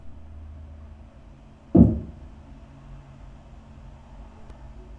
Um einen Vergleich zu haben, habe ich in meinem Wohnzimmer einen Hammer genommen und damit auf den Fußboden gehauen, wobei ich meine Aufnahmegeräte im Keller aufgestellt habe.
Im Vergleich zu oben gibt es kaum Energie im tieferen Frequenzbereich.